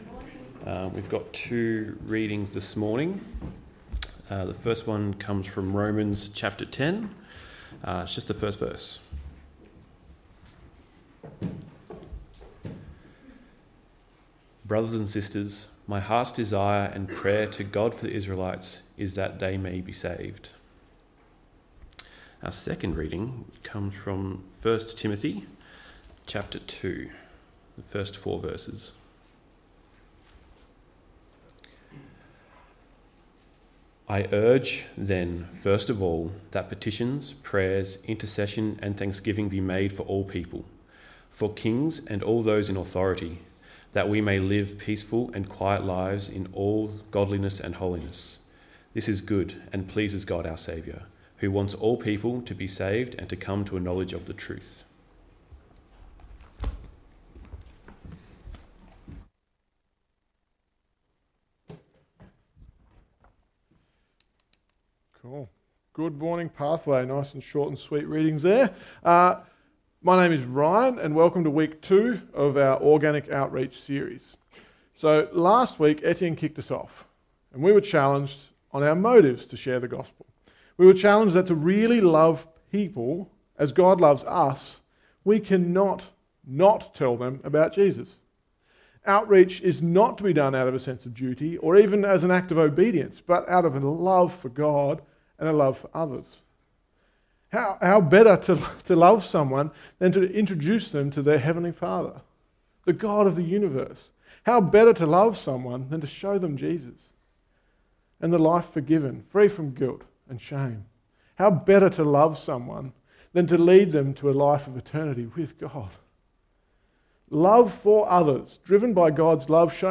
Text: Romans 10: 1, 1 Timothy 2: 1-4 Sermon